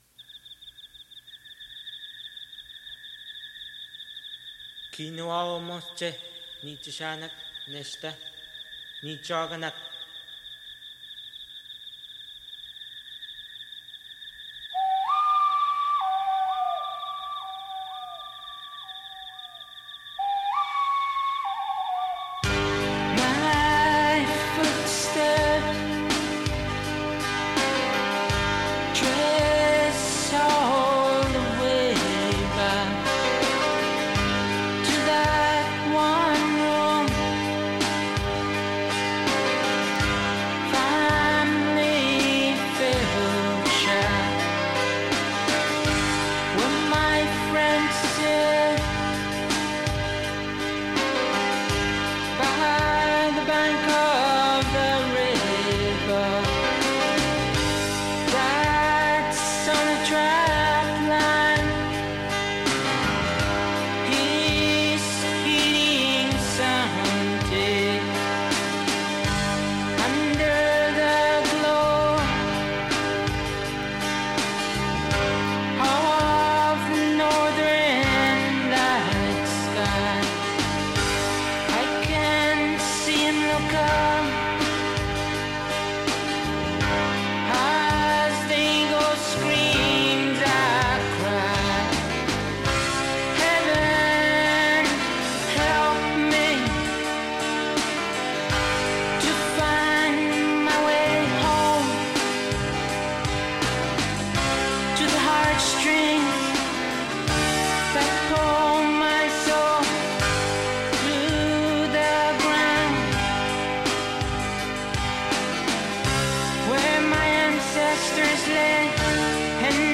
Vocals and guitars
Keyboard